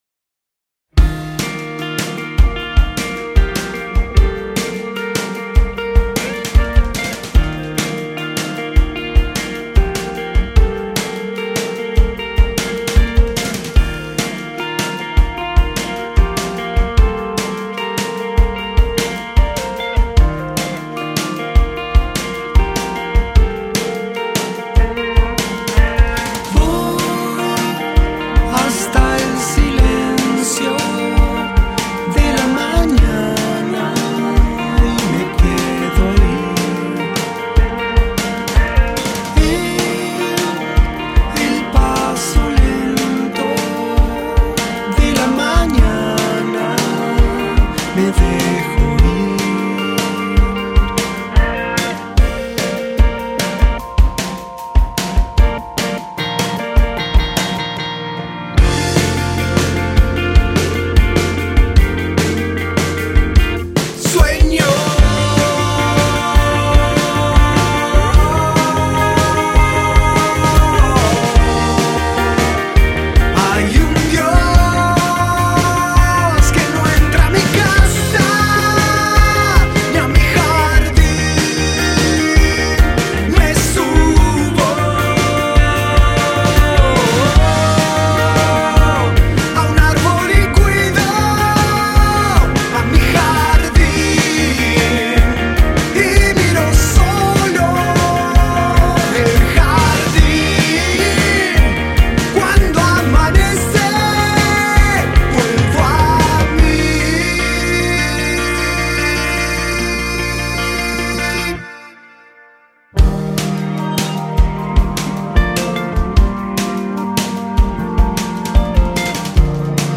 Guitarra eléctrica, guitarra acústica, programaciones y voz
Guitarra y voz
Batería
Bajo